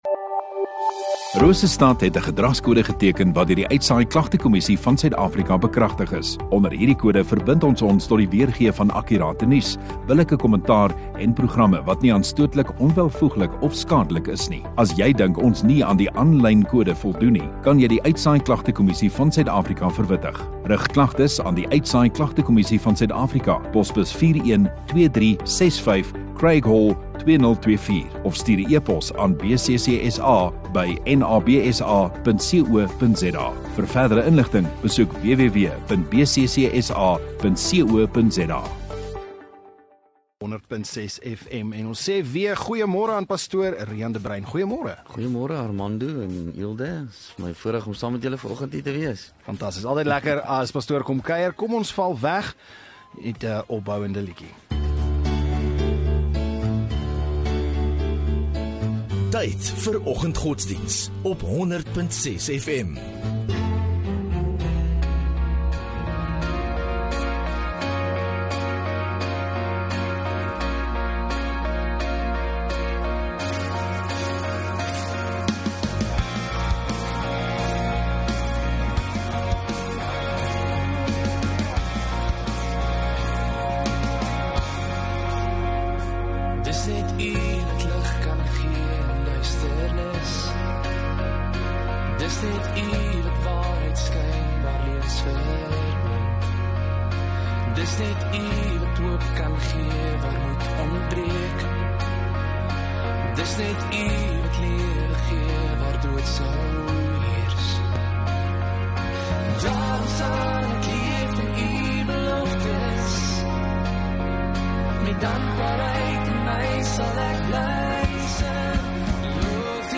Radio Rosestad View Promo Continue Radio Rosestad Install Rosestad Godsdiens 25 Nov Dinsdag Oggenddiens